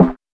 SNARE.112.NEPT.wav